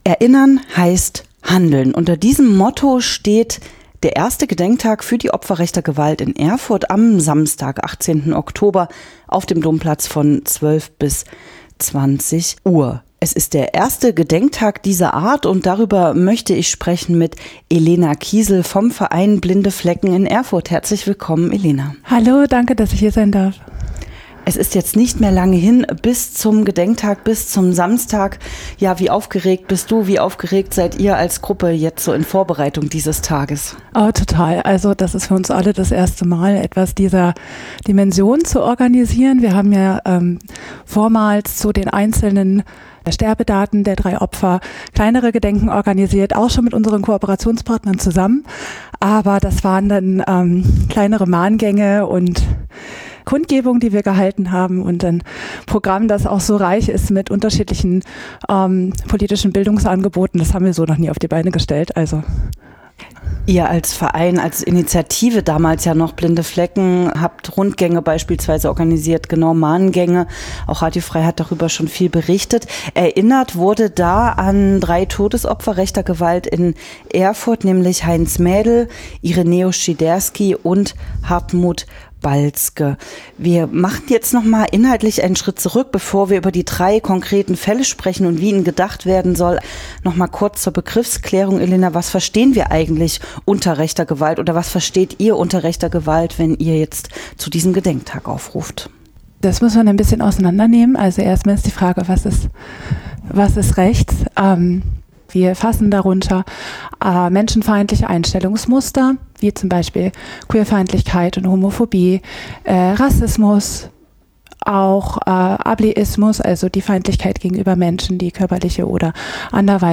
Interview Gedenktag_Blinde Flecken.mp3